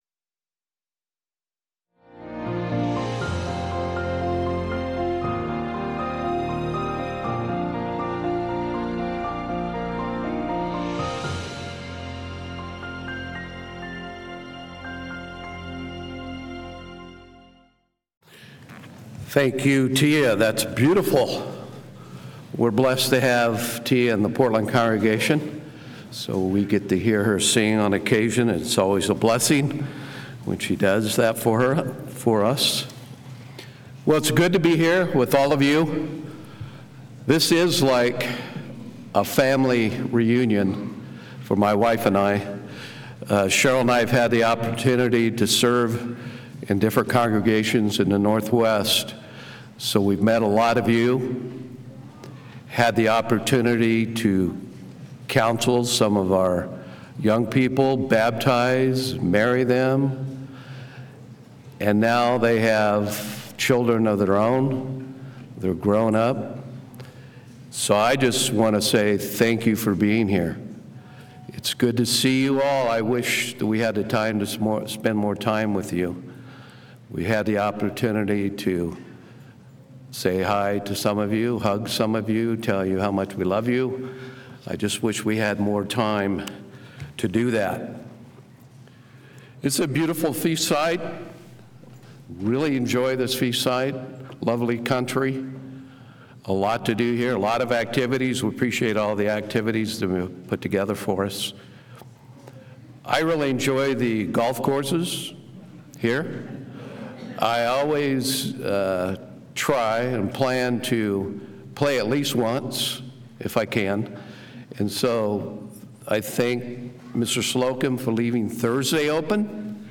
This sermon was given at the Spokane Valley, Washington 2023 Feast site.